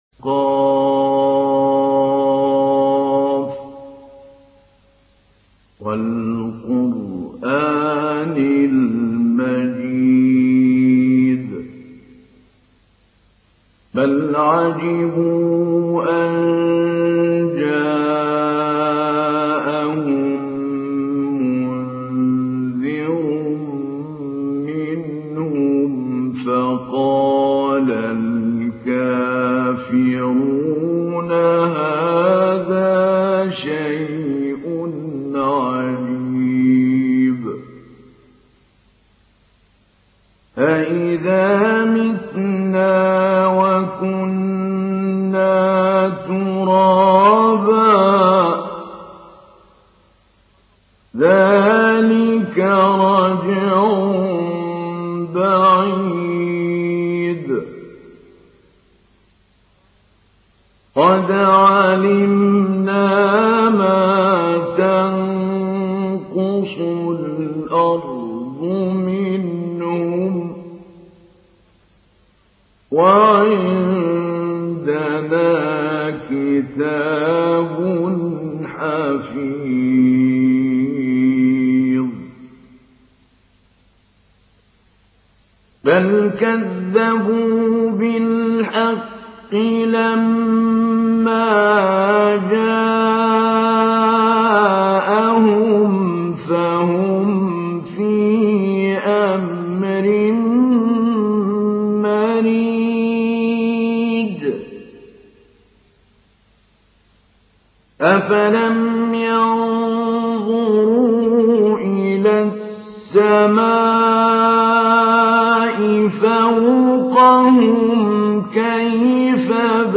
Surah Kaf MP3 in the Voice of Mahmoud Ali Albanna Mujawwad in Hafs Narration
Surah Kaf MP3 by Mahmoud Ali Albanna Mujawwad in Hafs An Asim narration. Listen and download the full recitation in MP3 format via direct and fast links in multiple qualities to your mobile phone.